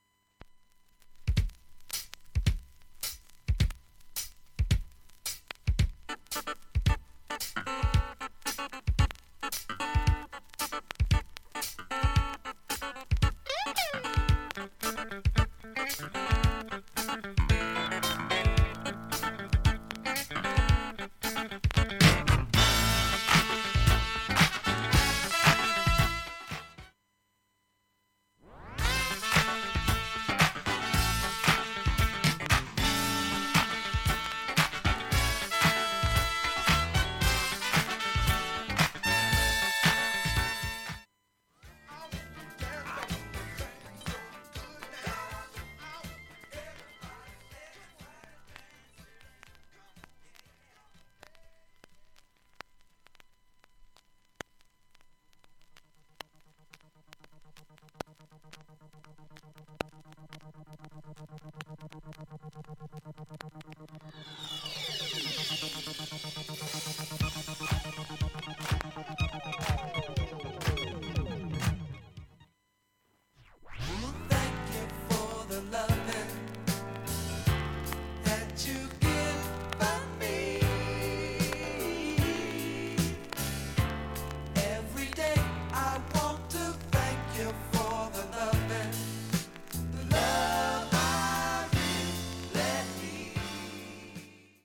音質目安にどうぞ B-2すべてとB-3序盤2ミリスレで大半で周回プツ 出ますがわずかなレベルです。